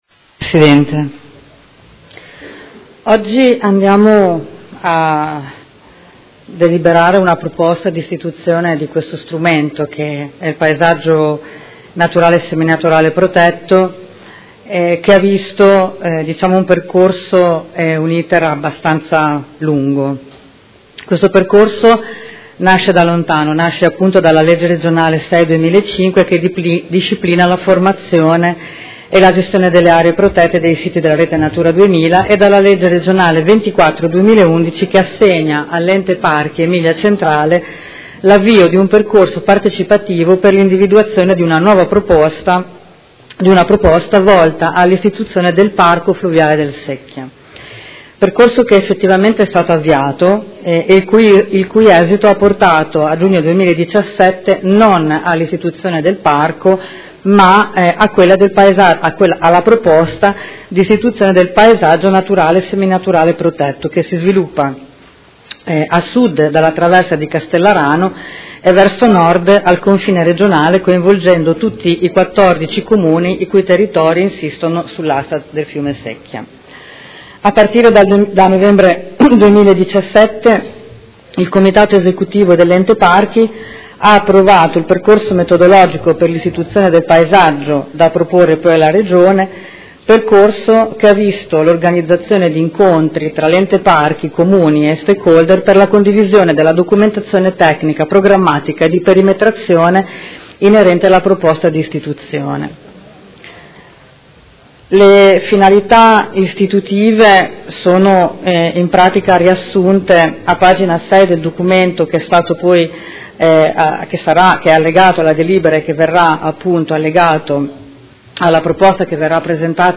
Alessandra Filippi — Sito Audio Consiglio Comunale
Seduta del 21/02/2019. Proposta di deliberazione: Proposta di istituzione del Paesaggio Naturale Seminaturale Protetto nel medio e basso corso del fiume Secchia ai sensi degli artt. 50-52 della L.R. n. 6/2005